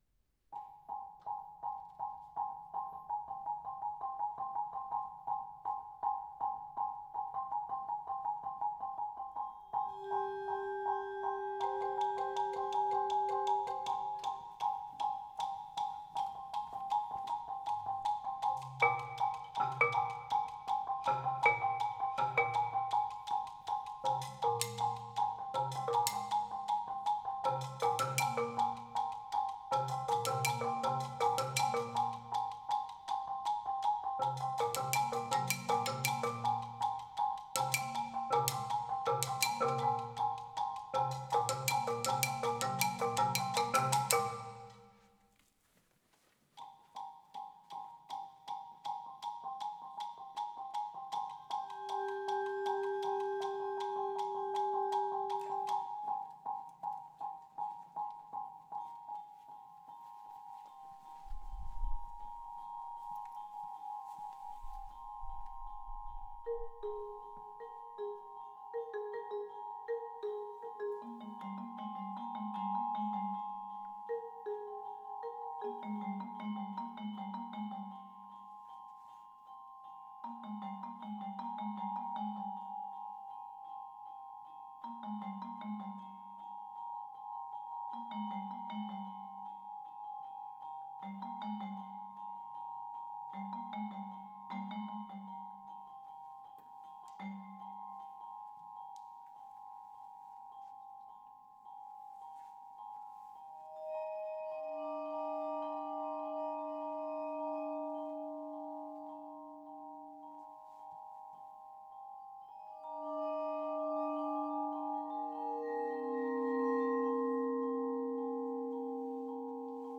Voicing: Mallet Quartet